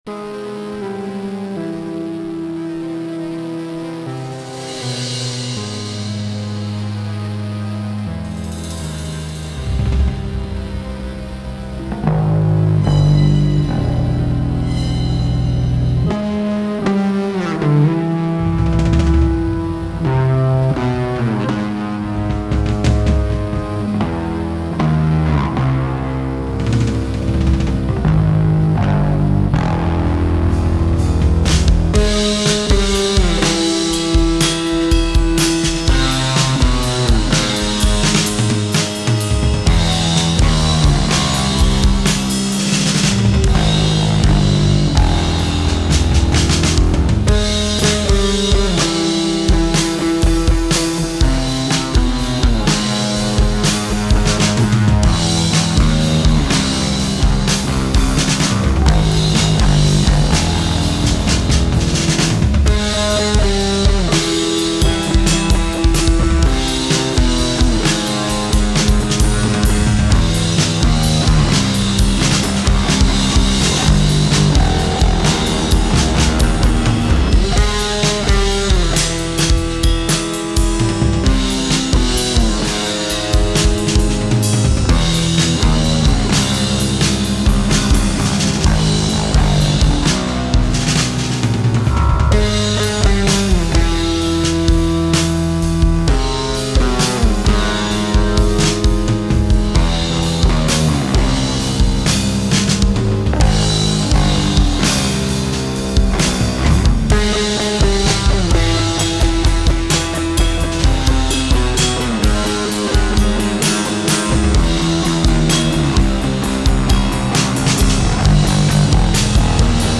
Long Descending Augmented Devil Line Rock